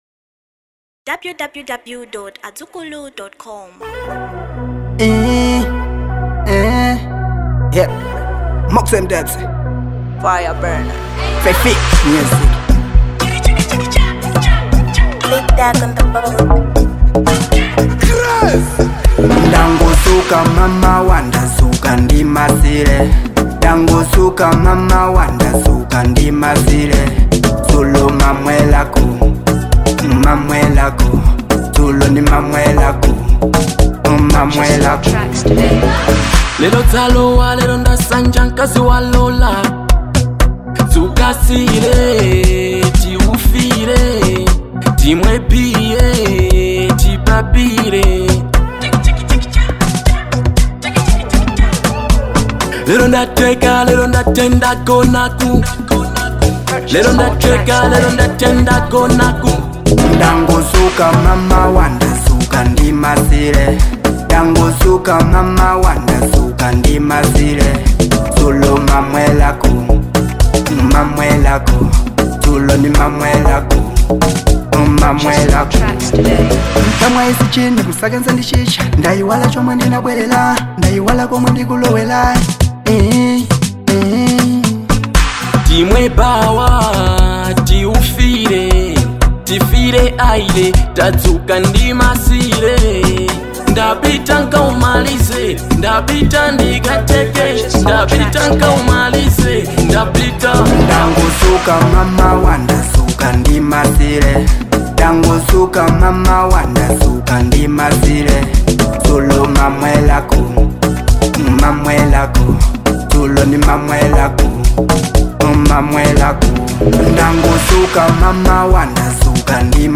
Reggae And Dancehall